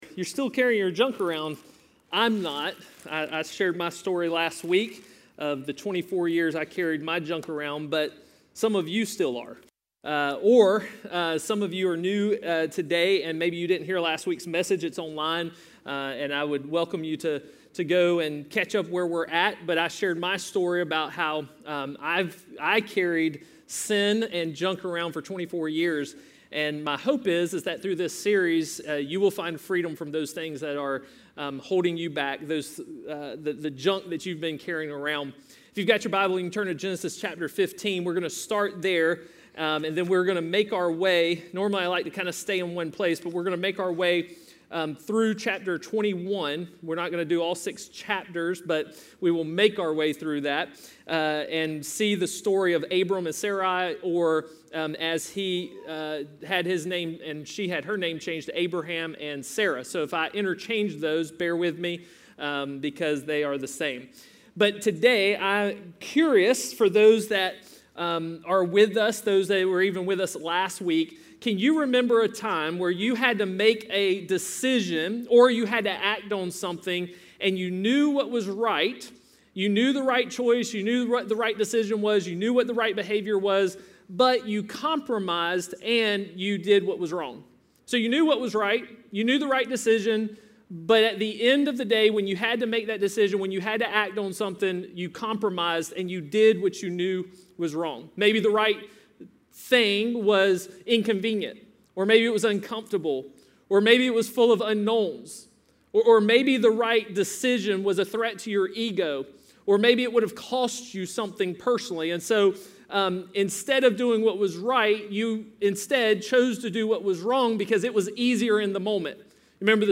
A message from the series "Bold."